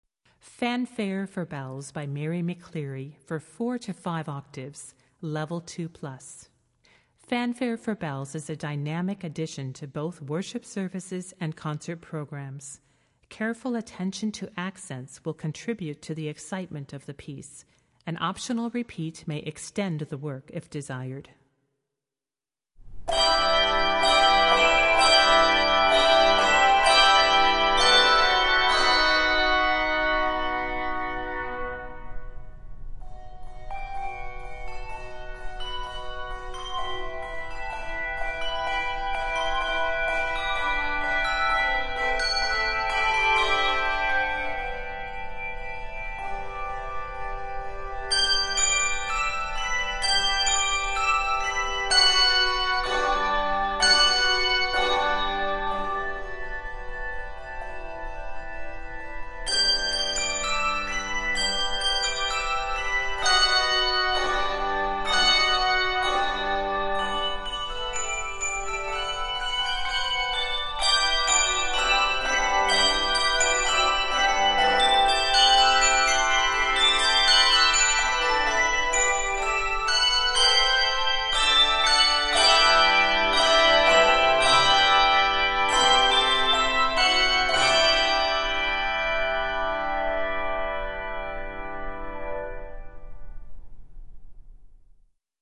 Voicing: Handbells 4-5 Octave